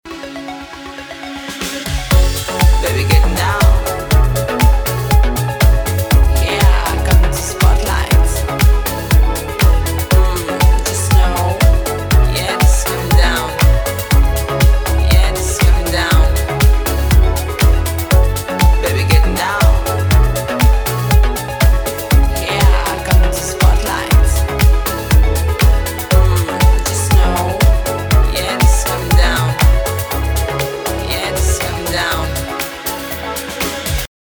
• Качество: 320, Stereo
deep house
женский голос
спокойные
чувственные